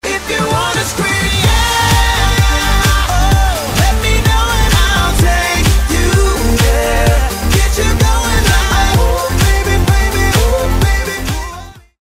GenrePop